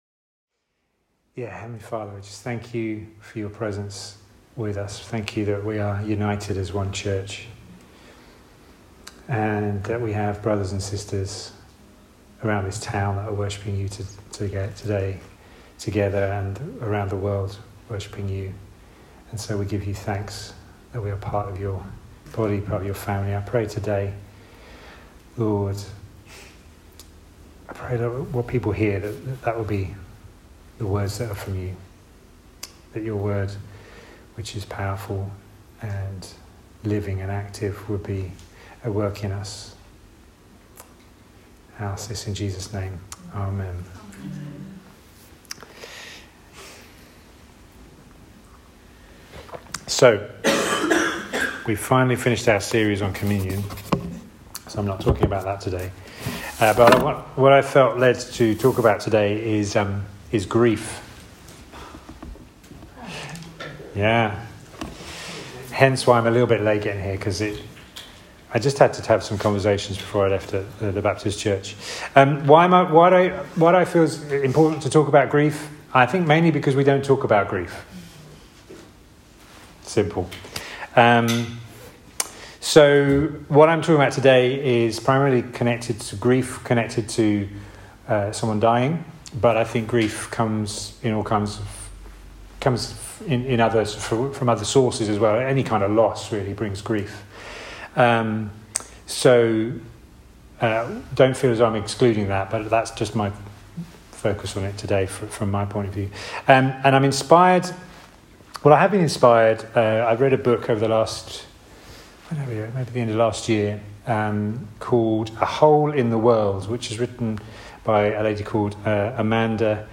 Weekly message from The King’s Church.